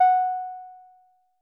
BASS1 F#5.wav